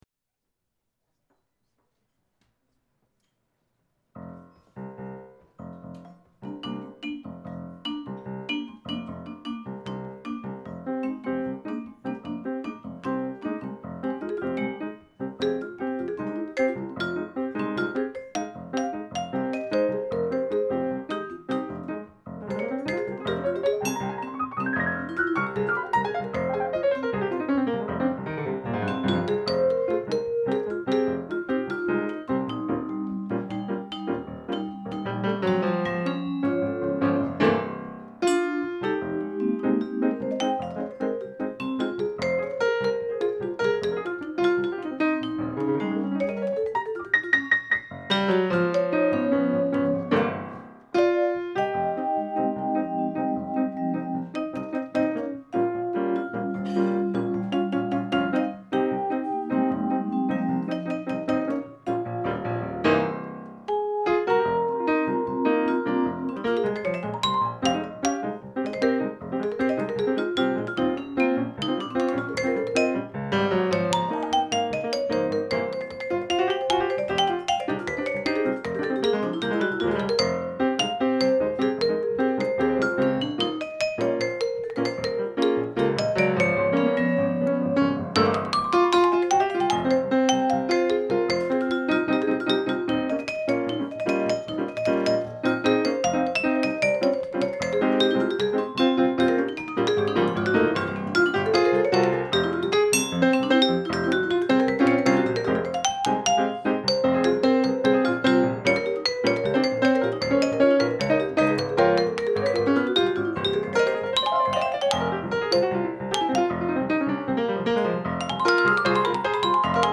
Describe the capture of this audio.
Live at Jazz at Lincoln Center Dizzy's Club Coca Cola